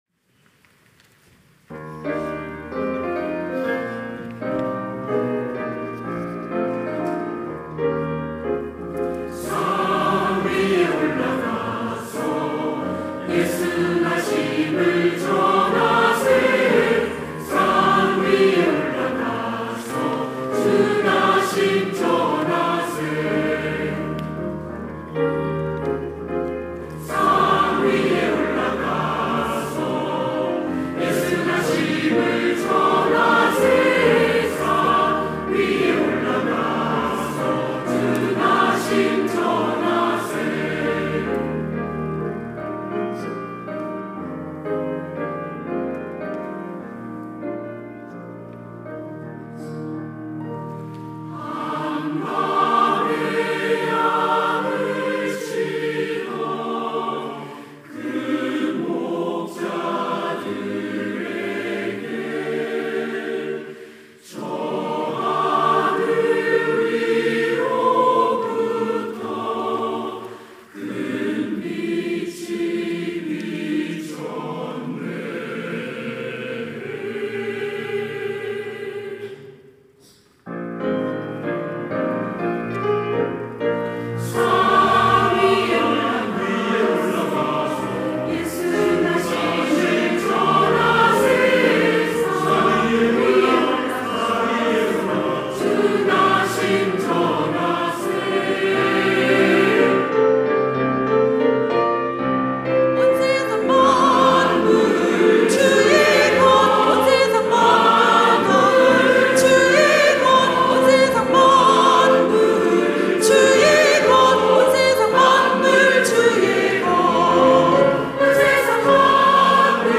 시온(주일1부) - 산 위에 올라가서
찬양대